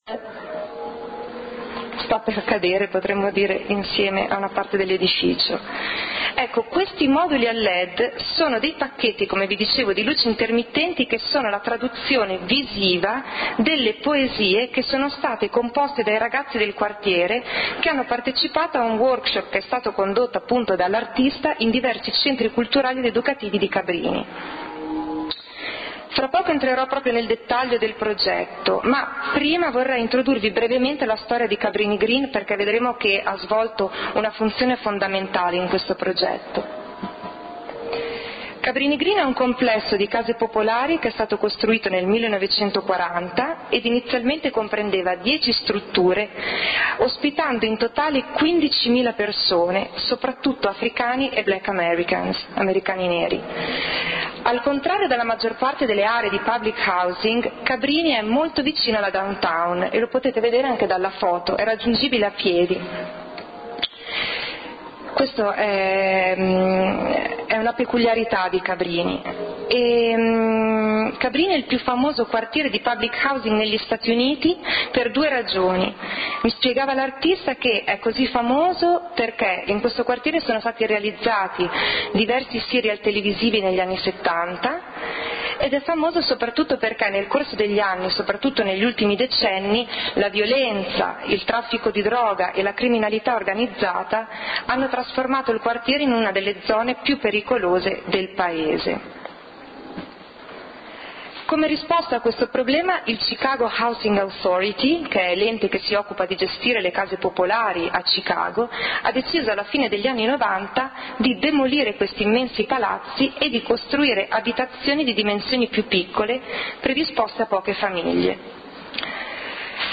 LECTURE / Pratiche artistiche, dispositivi educativi e forme della partecipazione